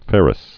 (fĕrĭs)